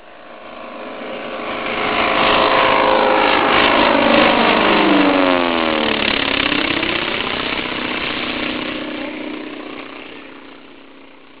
The Original Power: Pratt & Whitney Twin Wasp 14 Cylinder